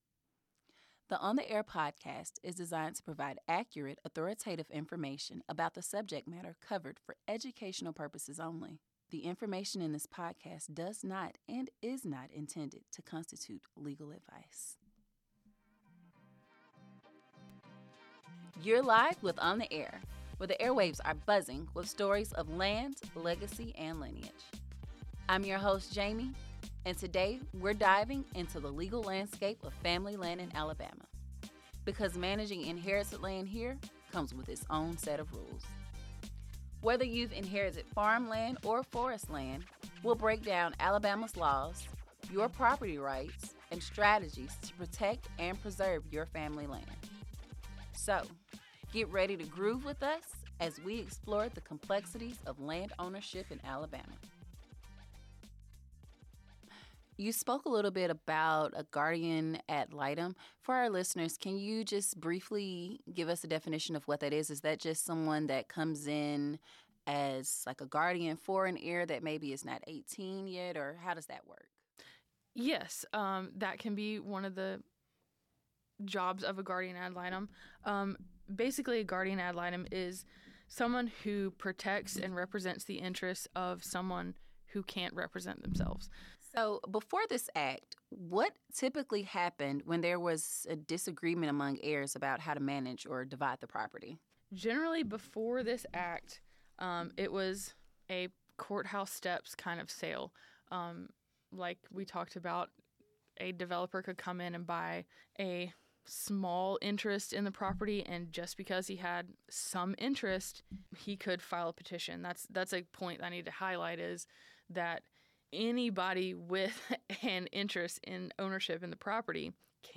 In this episode, the conversation about the Uniform Partition of Heirs Property Act continues.